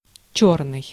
Ääntäminen
Tuntematon aksentti: IPA: [ˈt͡ɕɵrnɨj] IPA: /ˈʨɵr.nɨj/